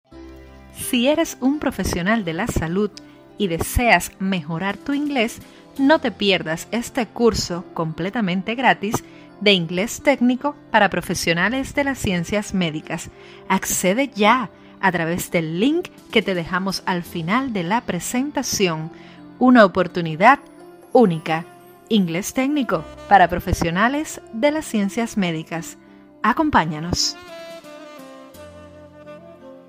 Locutora y narradora Voice over
Kein Dialekt
Sprechprobe: eLearning (Muttersprache):